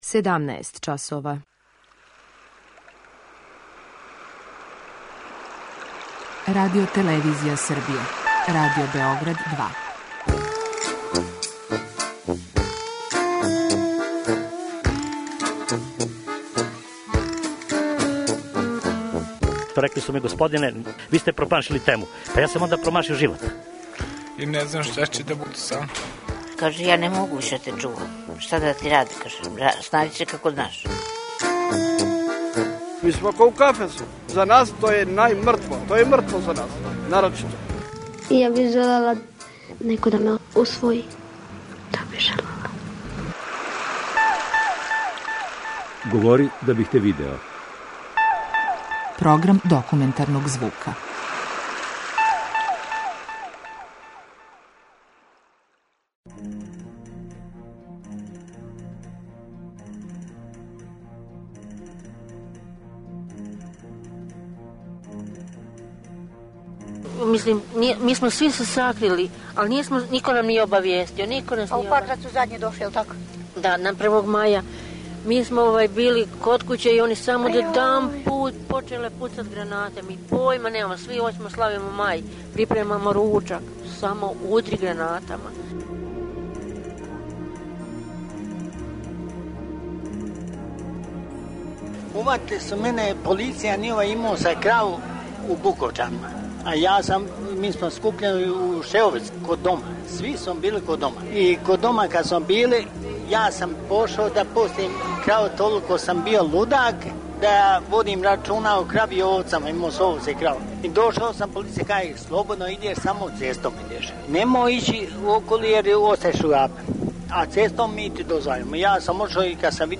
Документарни програм
Снимано на месту догађаја маја 1995. године.